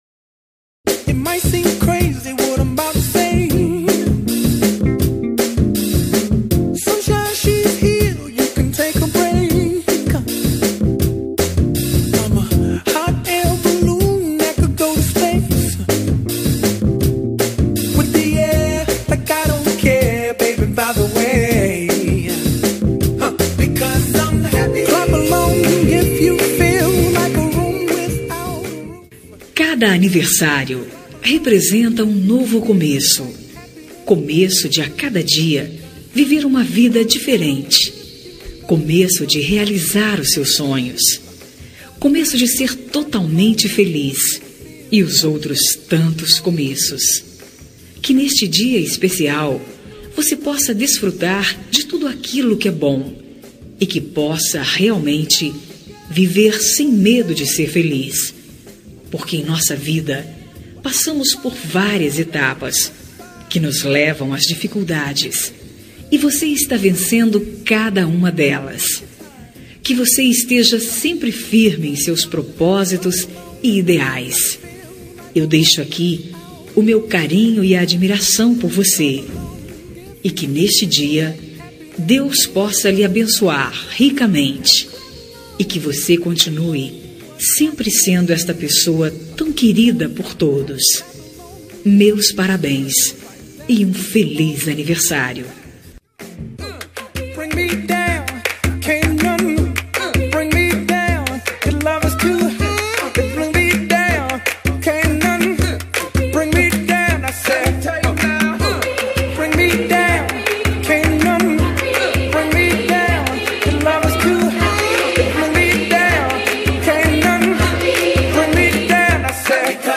Aniversário de Pessoa Especial – Voz Feminina – Cód: 4188